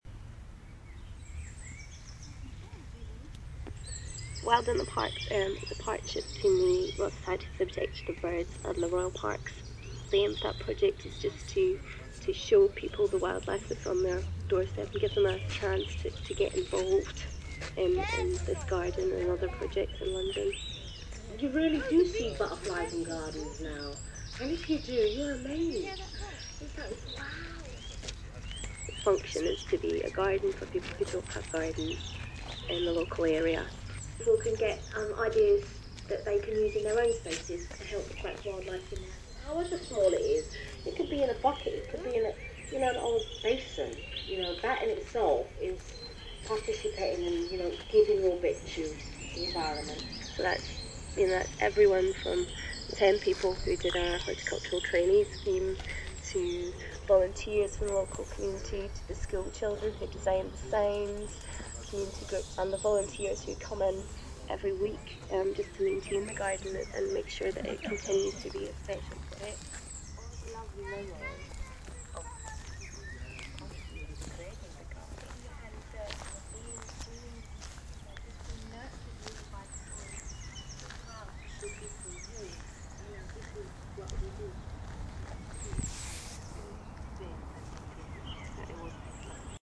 sound bench - regent's park
5518-sound-bench-regent-s-park.mp3